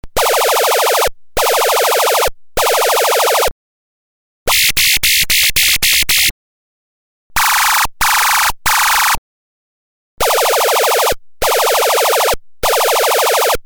「９５６１基板」の音色をスイッチで変更して、１６通りのサウンドを発生することのできる基板です。
上記サンプル音は下記Ｎｏ．０〜Ｎｏ．３の順番で再生されます。
機関銃
虫の鳴き声
クリケット
重機関銃